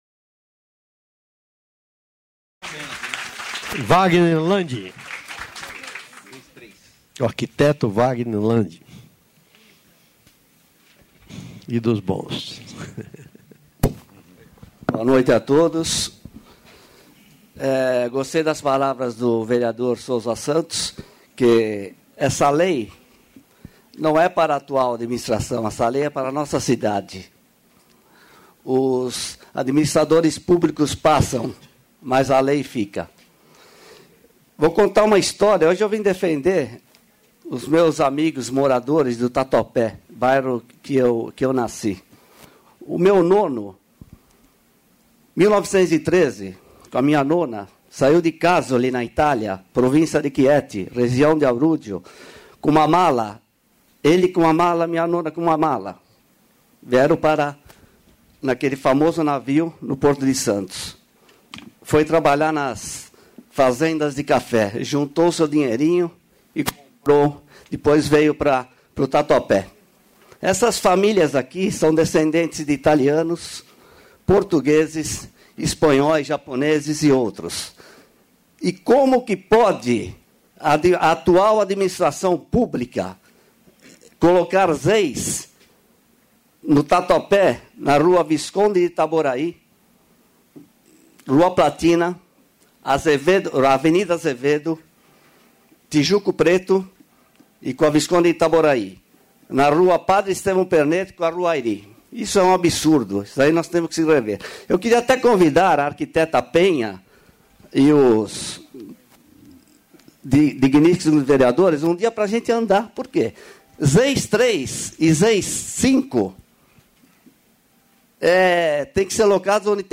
Audiências Públicas e ZEIS na Região da Subprefeitura da Moóca.